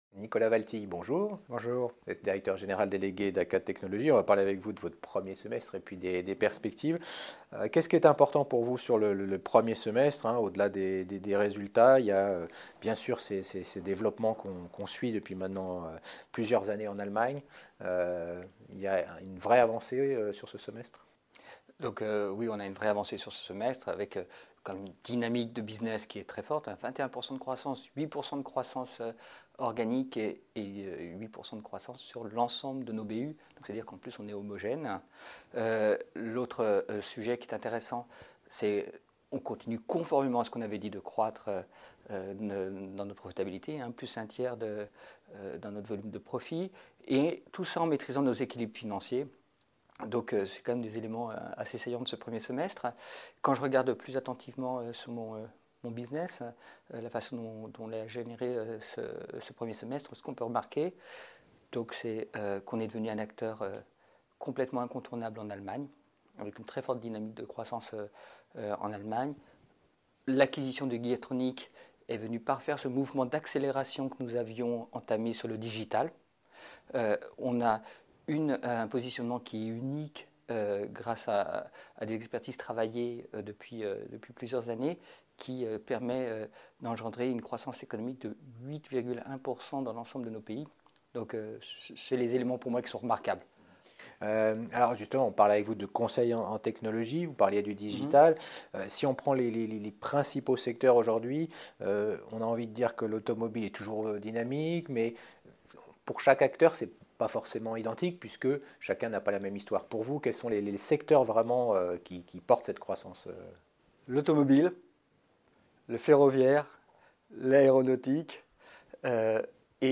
L’interview complète :